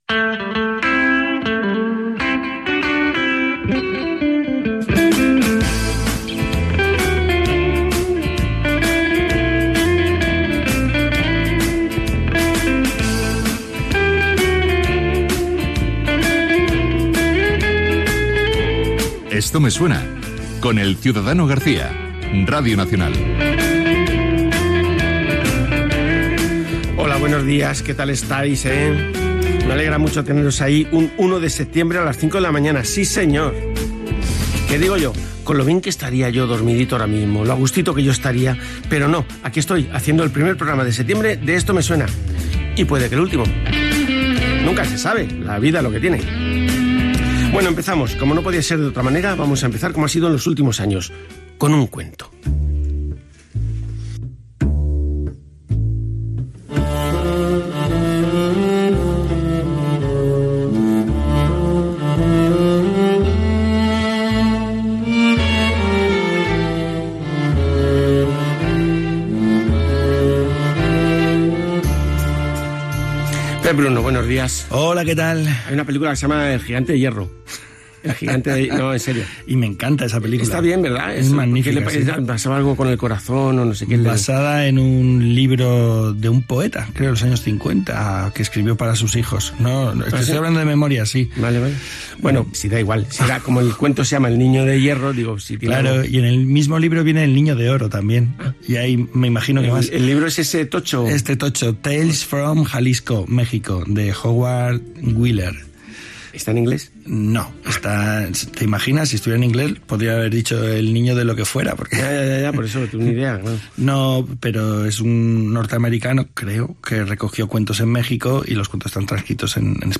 Fragment de la darrera emissió del programa, després de 12 temporades.
Indiactiu del programa, hora, comentari, explicació d'un conte.
Entreteniment